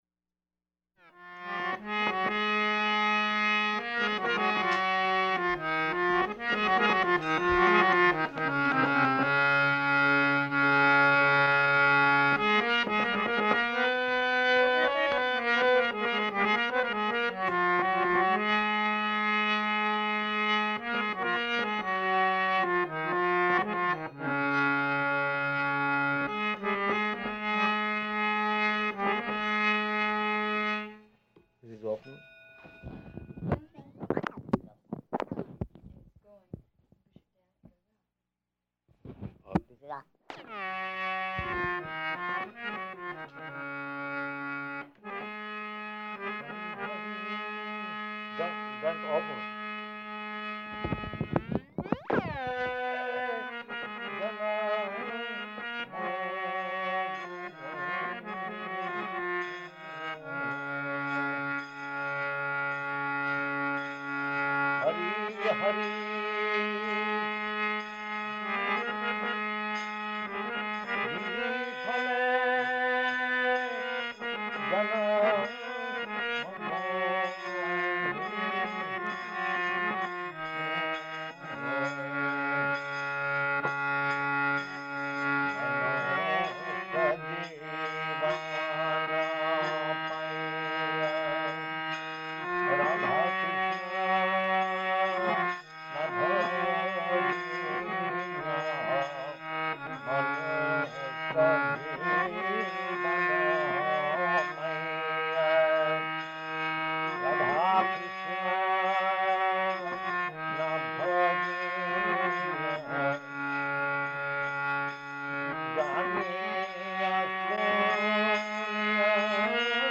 Type: Purport
Location: Los Angeles
[sings Hari hari biphale, accompanied by harmonium]